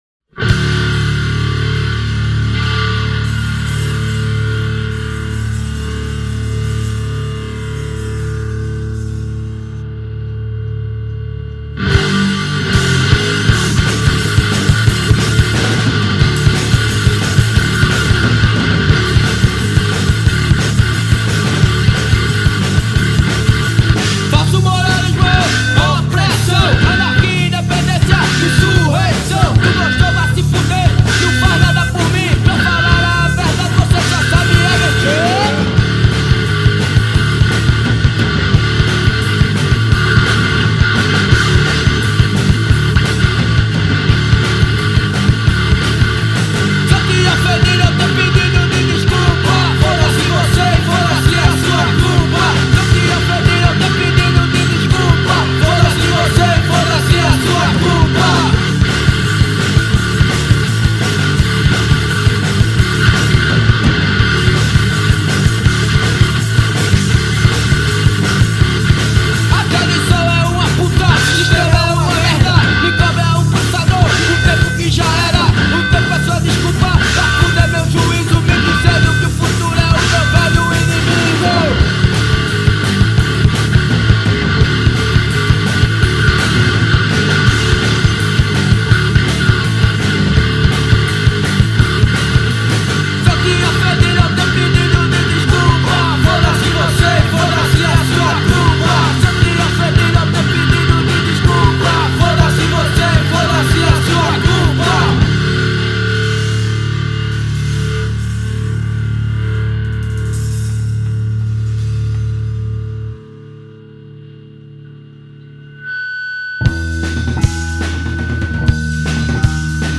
Som direto, sem excesso, com peso e intenção.